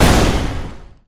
Explosion.mp3